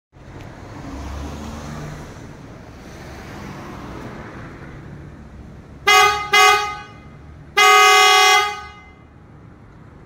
BASS HORN OTO STYLE (390/430Hz)
Низкочастотные звуковые сигналы PIAA выполнены с защитой от влаги и соответствуют требованиям европейского стандарта ECE R28, обеспечивая длительный срок службы.
Обладает частотой 390Hz + 430Hz. Звуковое давление 112дБ.